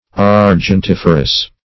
\Ar`gen*tif"er*ous\